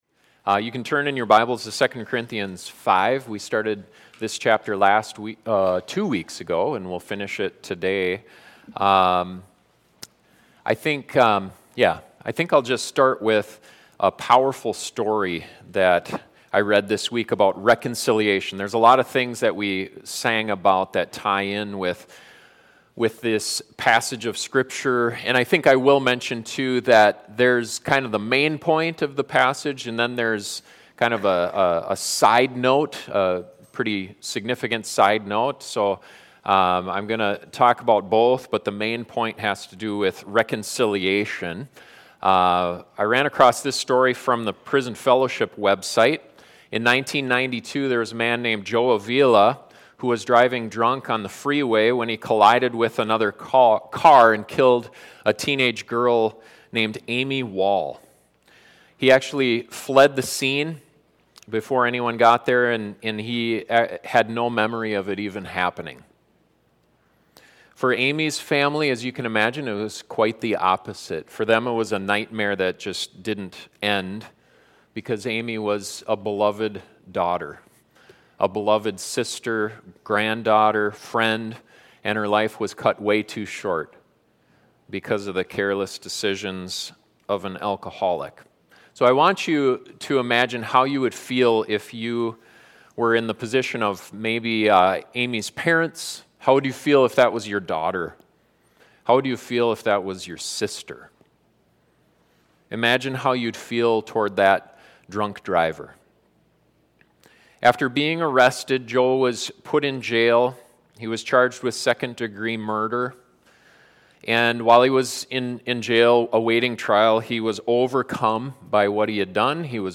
This sermon looks at the greatest reconciliation story ever told – a story we have been called to be partners in.